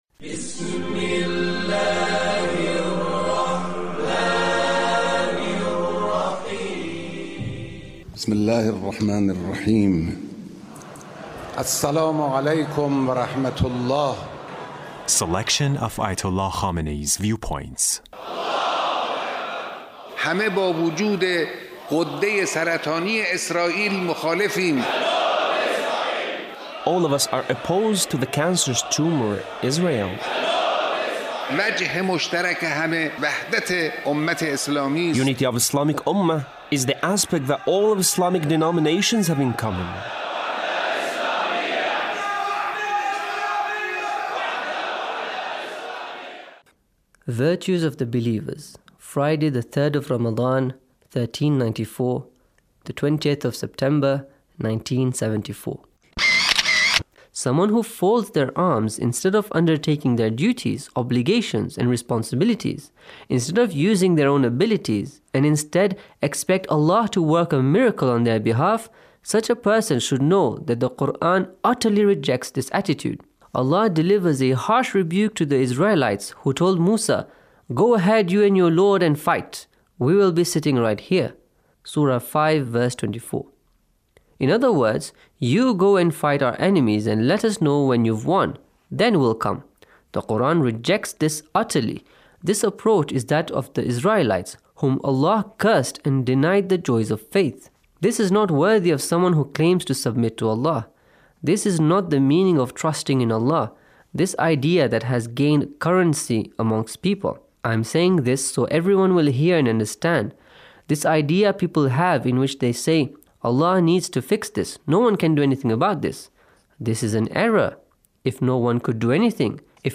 Leader's Speech on Taqwa